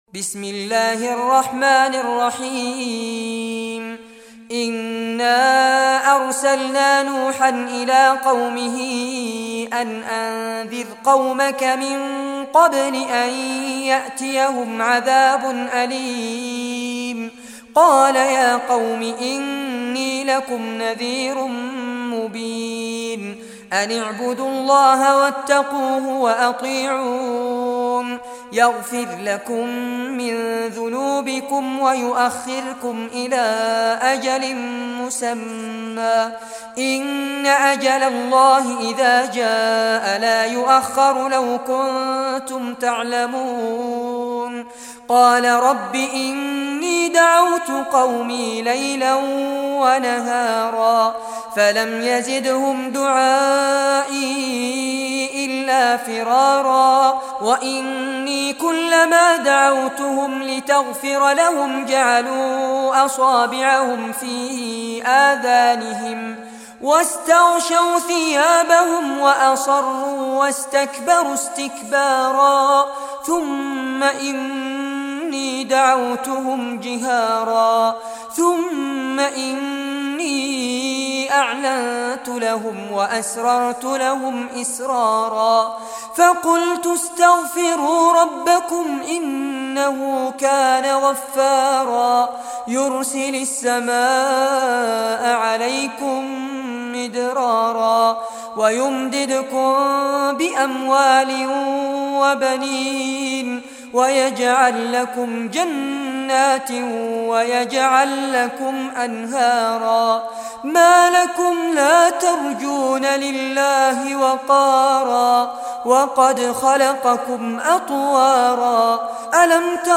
Surah Nuh Recitation by Fares Abbad
Surah Nuh, listen or play online mp3 tilawat / recitation in Arabic in the beautiful voice of Sheikh Fares Abbad.
71-surah-nuh.mp3